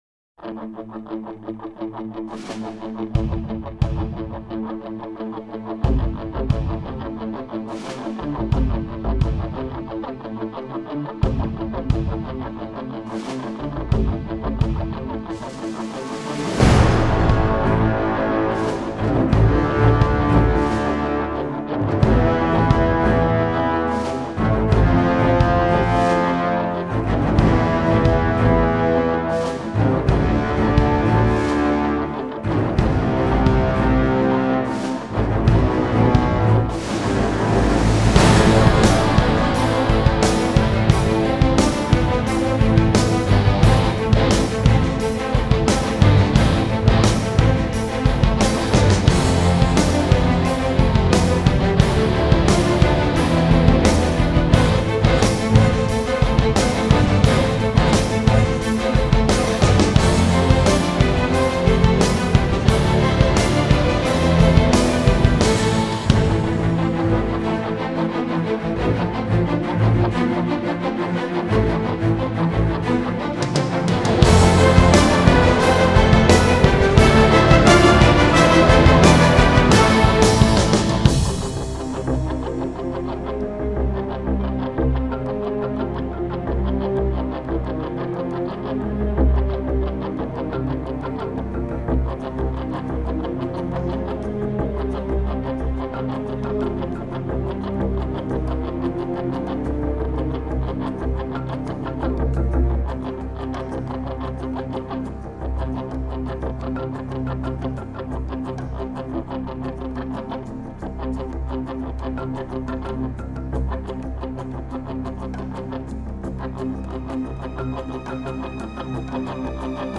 Тип:Score